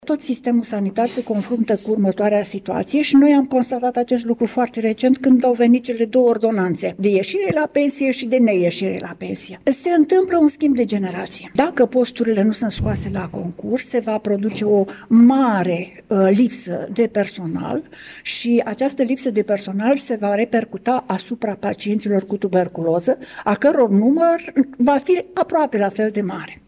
Astăzi, într-o conferință de presă organizată cu prilejul Zilei Mondiale de Luptă împotriva Tuberculozei